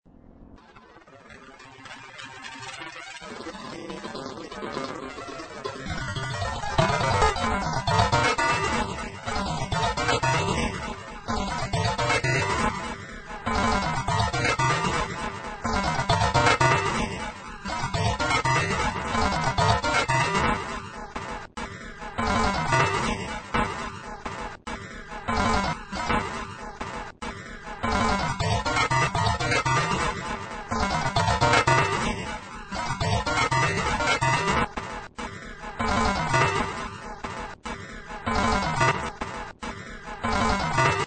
My Musical Compositions samples in MP3pro I am mostly interested by experimental music, based on a non conventional approach.